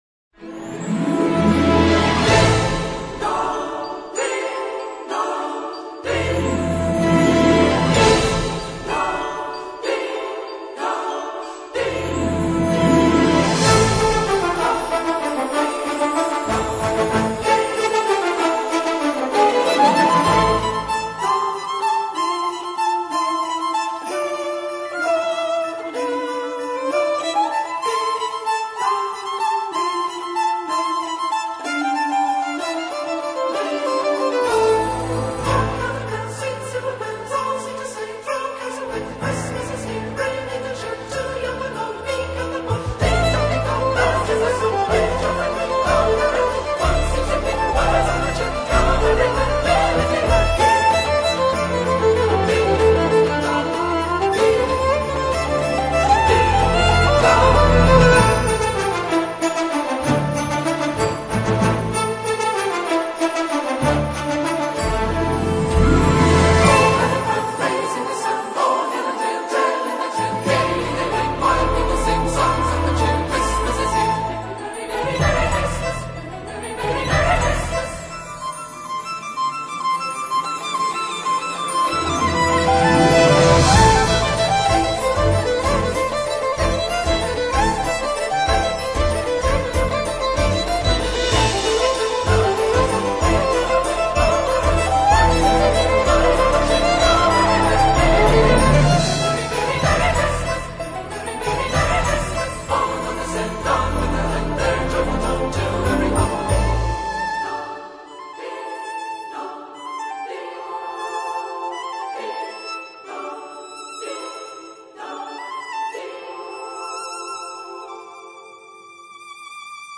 ORCHESTRA